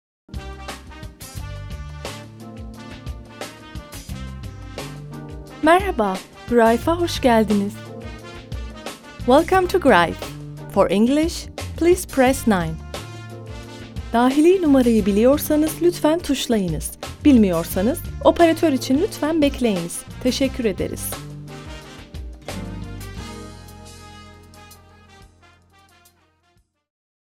Voice over Dubbing female cartoons
Sprechprobe: Industrie (Muttersprache):